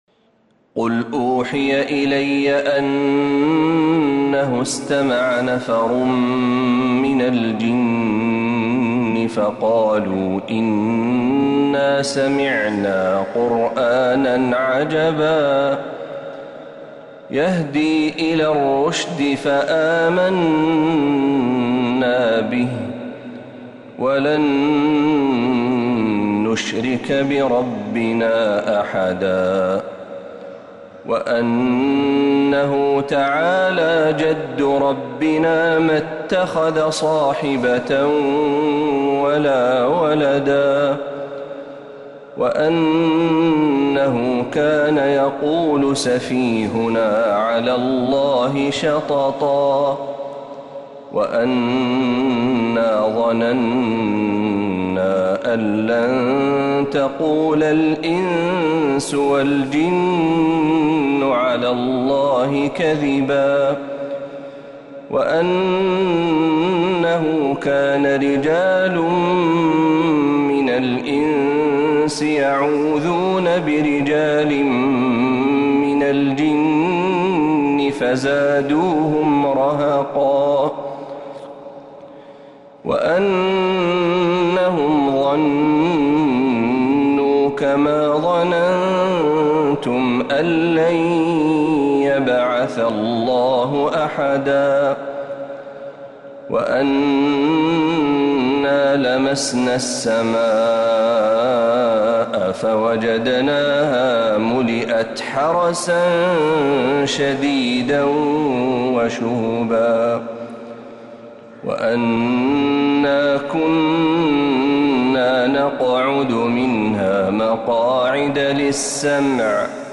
سورة الجن كاملة من الحرم النبوي > السور المكتملة للشيخ محمد برهجي من الحرم النبوي 🕌 > السور المكتملة 🕌 > المزيد - تلاوات الحرمين